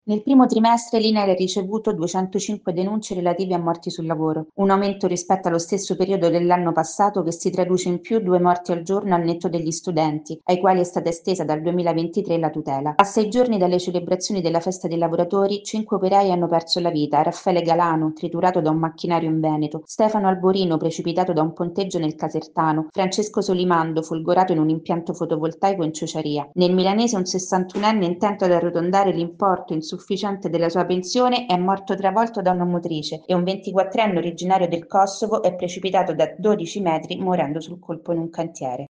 Economia